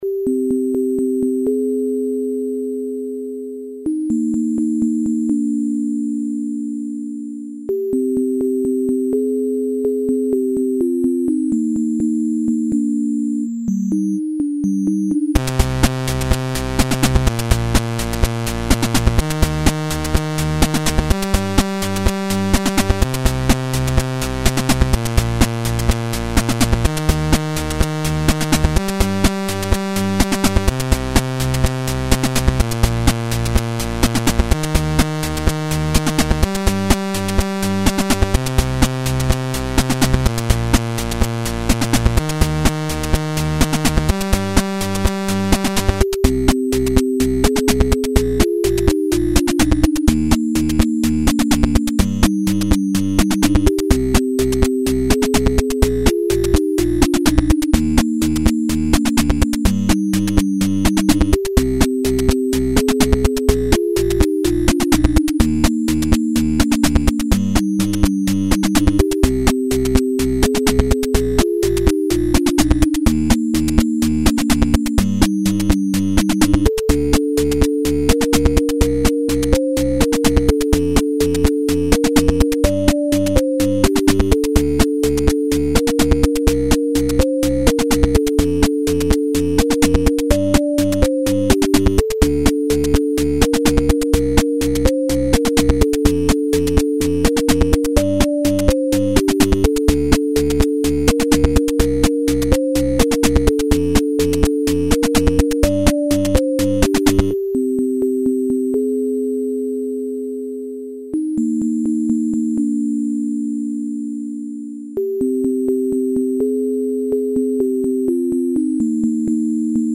Preview: New musical sequence performed with 8-bit sounds.